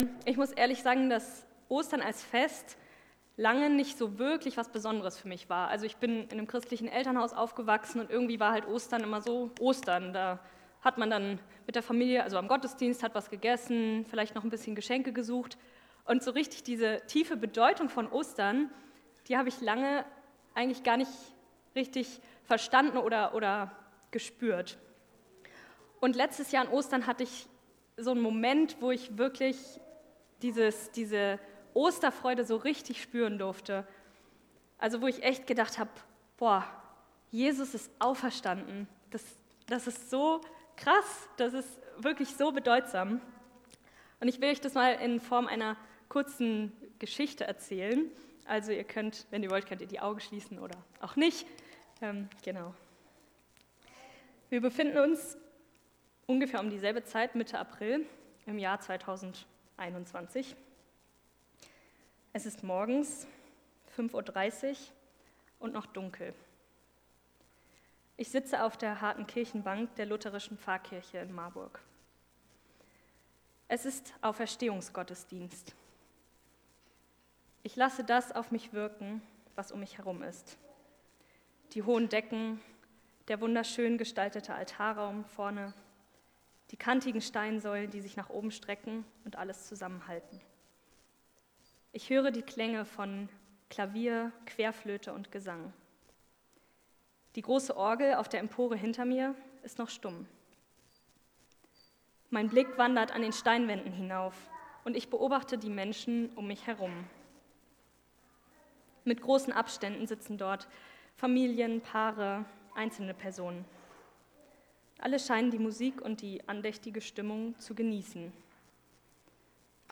Perspektivwechsel – Es ist vollbracht! | Marburger Predigten